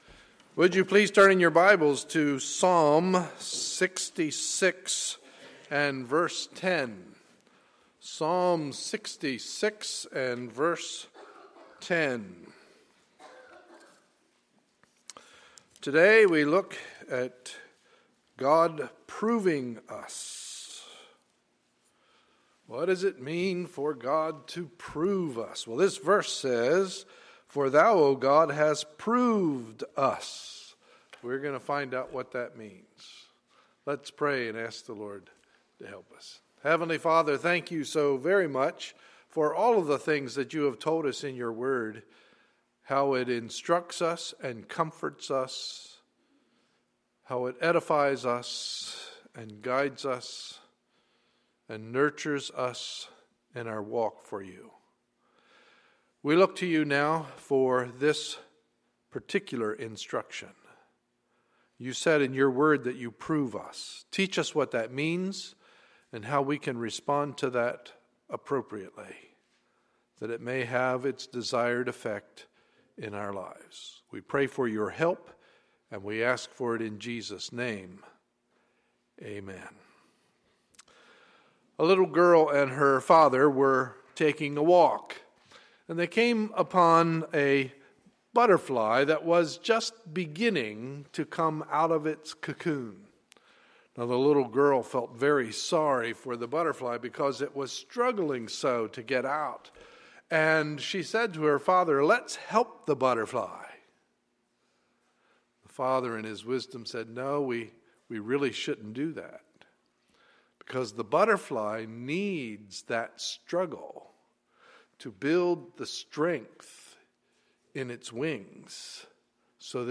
Sunday, November 17, 2013 – Morning Service